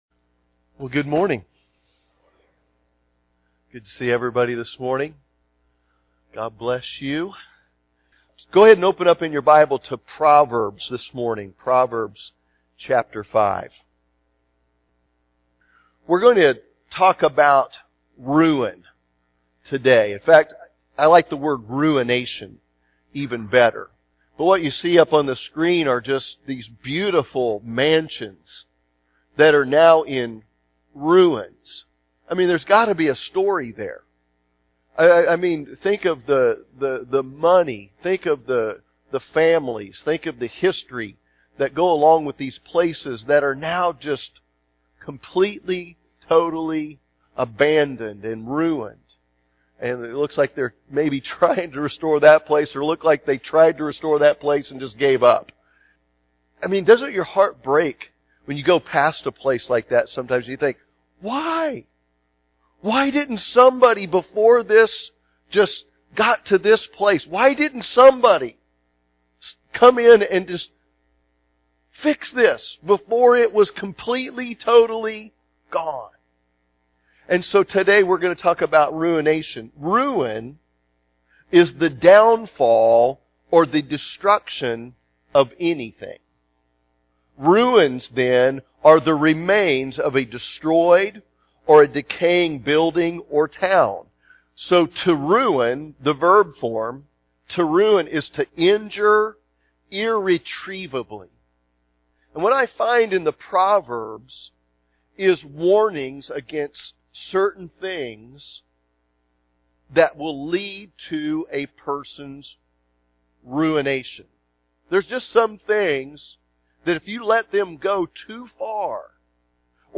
Sunday Morning Service
Sermon